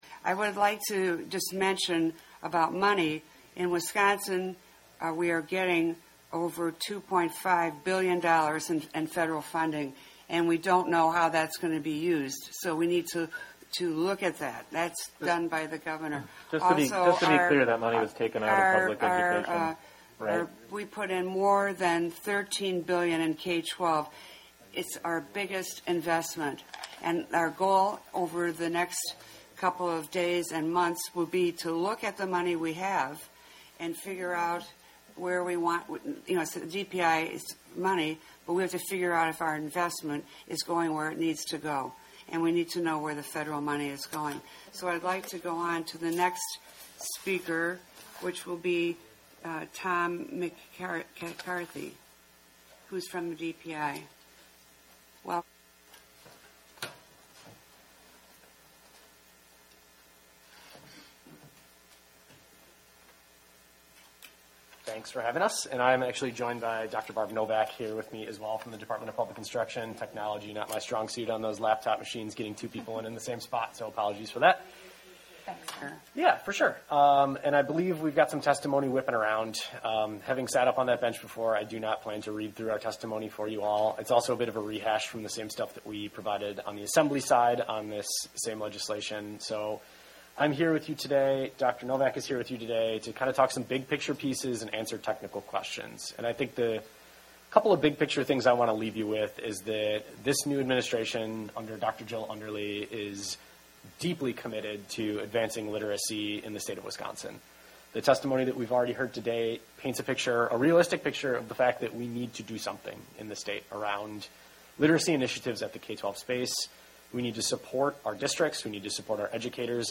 Wisconsin Senate SB454 reading readiness assessments: DPI Testimony